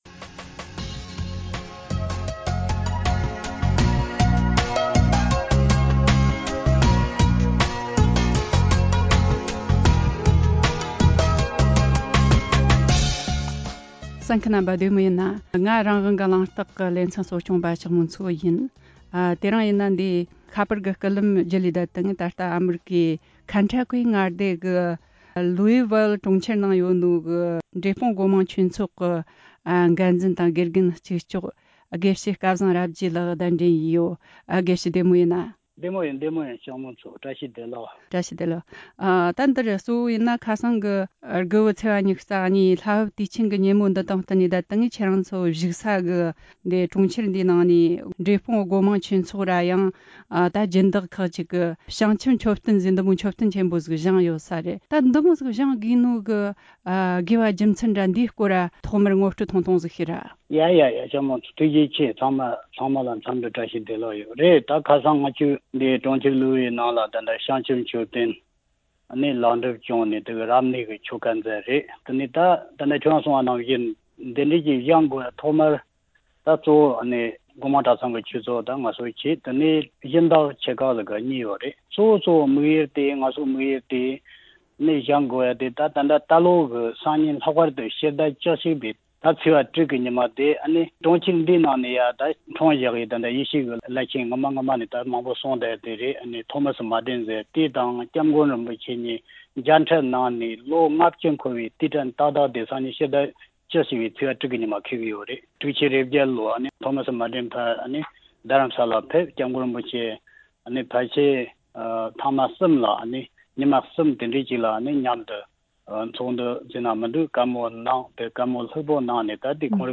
གླེང་མོལ་བྱས་བར་གསན་རོགས་གནོངས།།